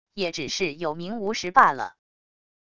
也只是有名无实罢了wav音频生成系统WAV Audio Player